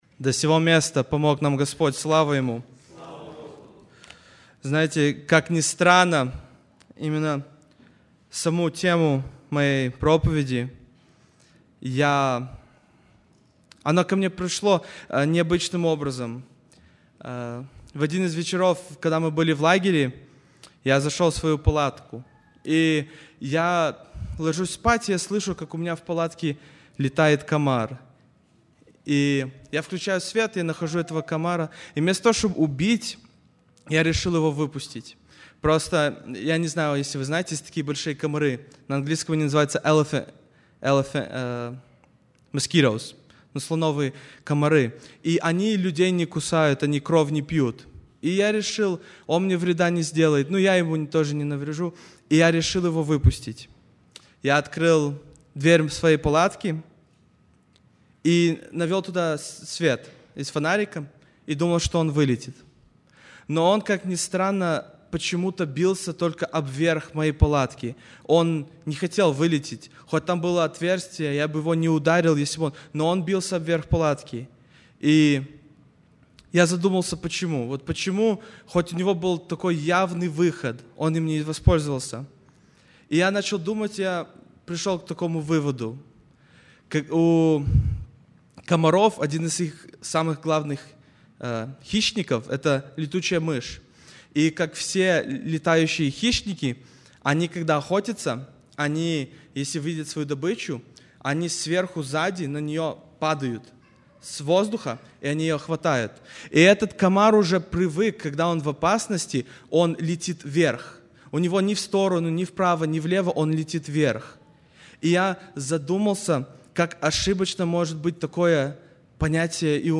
02+Проповедь.mp3